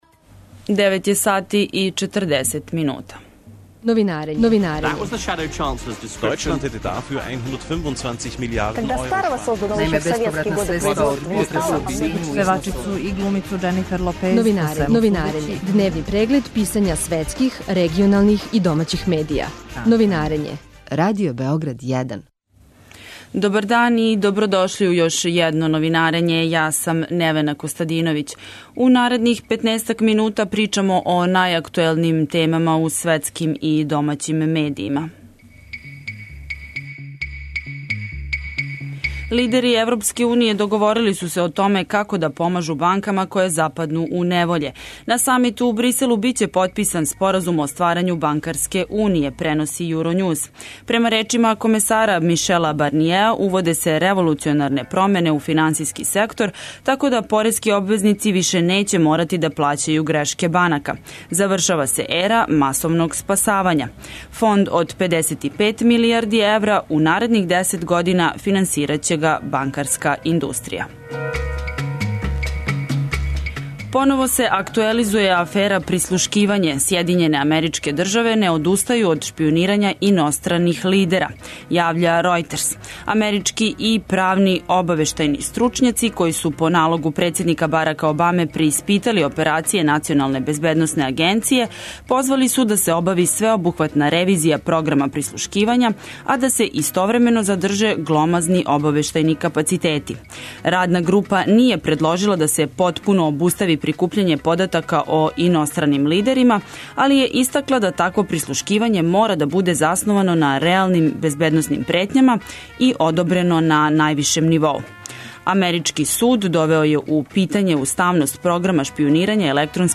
Гост у студију је познавалац америчке спољне и безбедносне политике